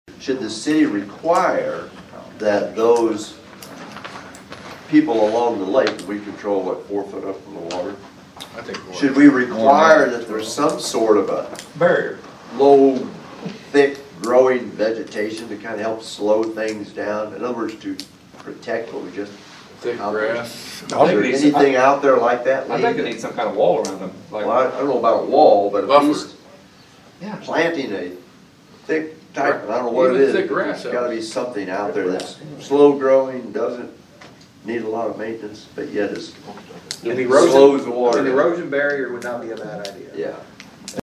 And, Alderman Andy Lester also had some thoughts on what may need to be done after the project was completed.